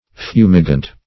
Fumigant \Fu"mi*gant\, a. [L. fumigans, p. pr. of fumigare.